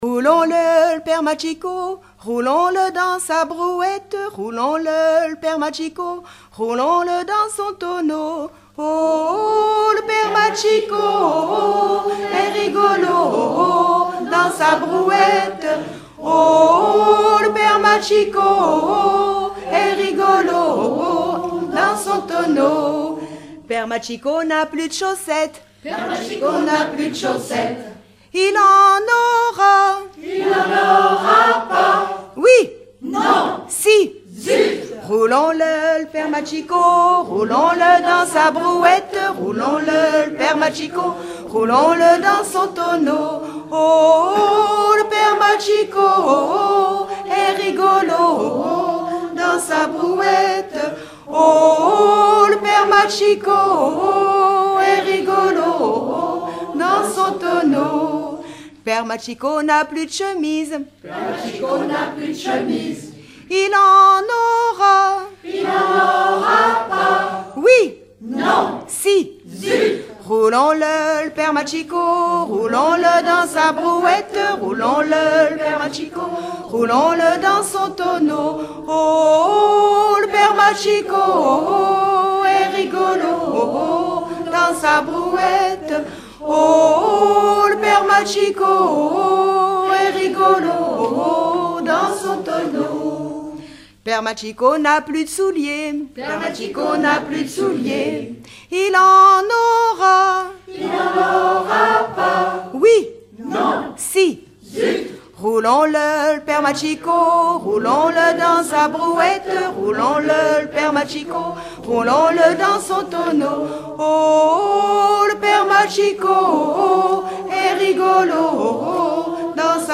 Genre énumérative
Regroupement de chanteurs du canton
Pièce musicale inédite